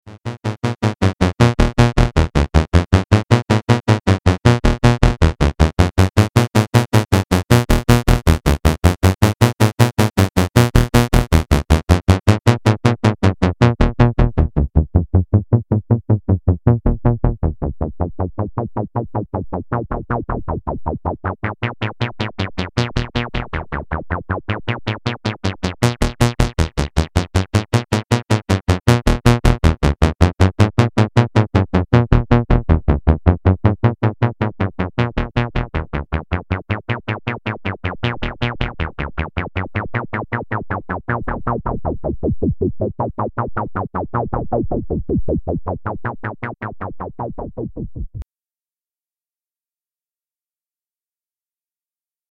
Rubberduck is het gratis softwareequivalent (niet echt natuurlijk volgens de kenners) van de Roland 303.
Helaas kwamen er alleen maar bubbels uit en bij nr 2000 is ie uit productie genomen als commerciele mislukking.